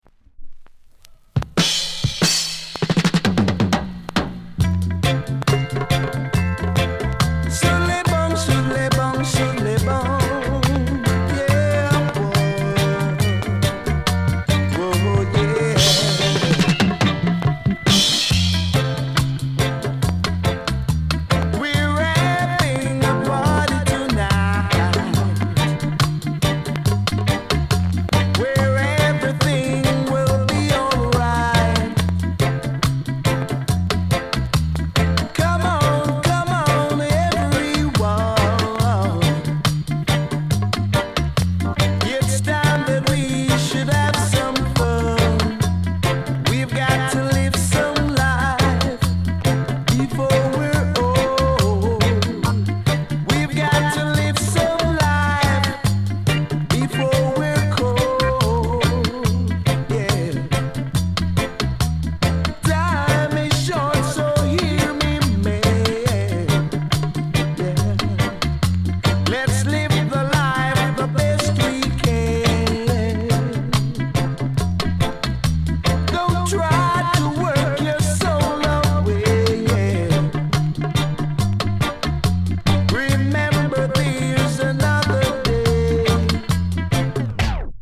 UK・英 7inch/45s
A面 あたまにキズ。パチつく箇所あり。飛びません。
類別 雷鬼